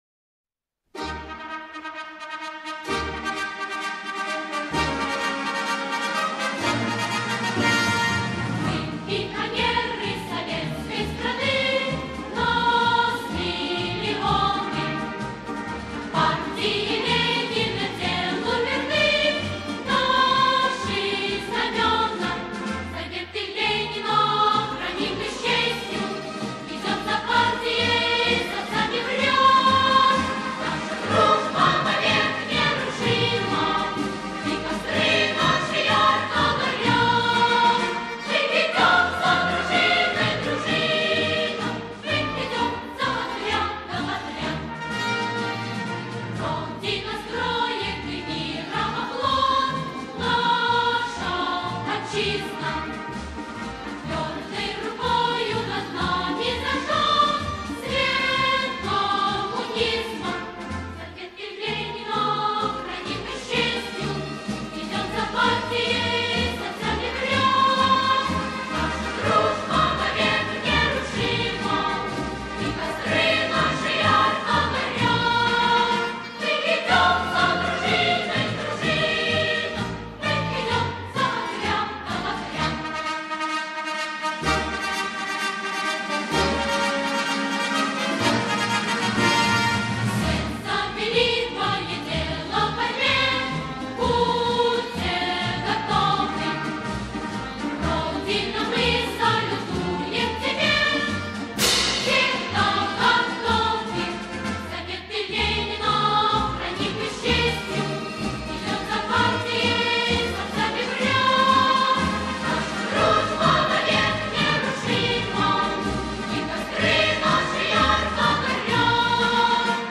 • Категория: Марши для детей
• Качество: Высокое